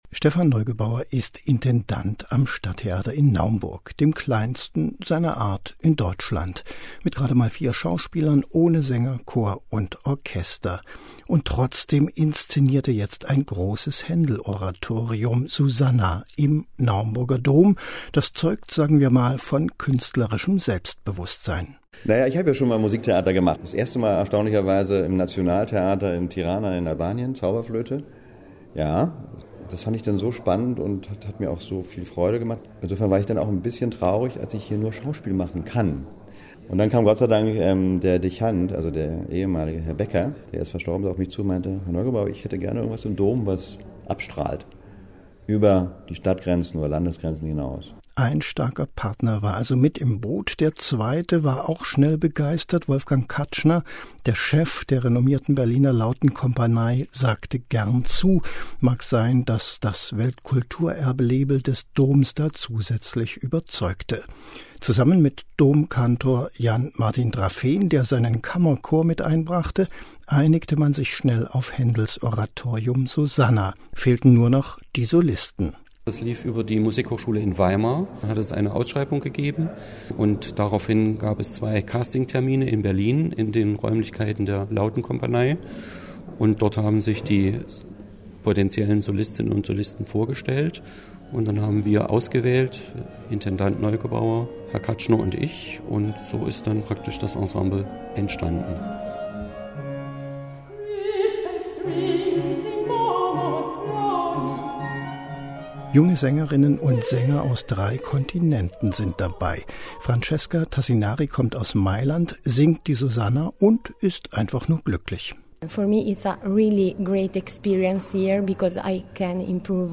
Zum Nachhören das Radio-Feature vom MDR Aktuell als Ankündigung der Vorstellungen zu SUSANNA.